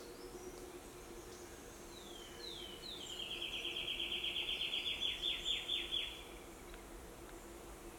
Arañero Ribereño (Myiothlypis rivularis)
Nombre en inglés: Riverbank Warbler
Localidad o área protegida: Parque Provincial Urugua-í
Condición: Silvestre
Certeza: Fotografiada, Vocalización Grabada